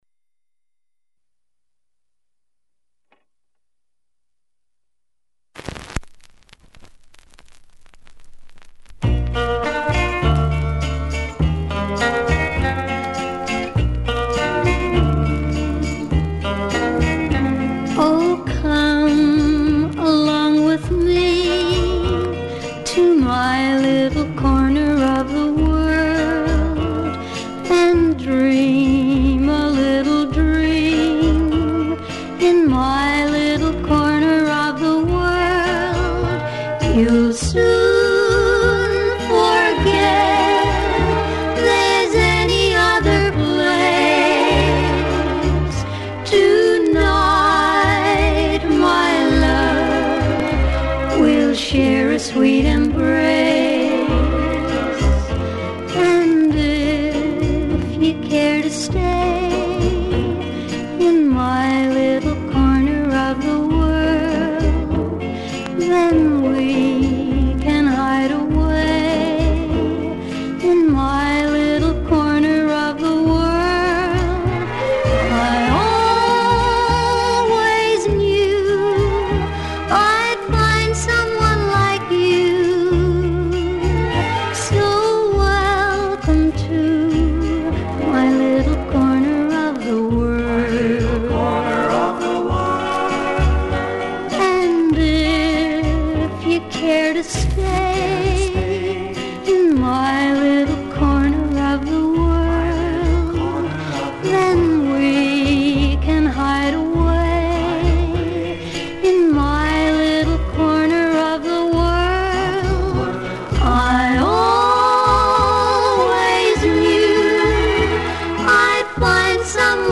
recorded from vinyl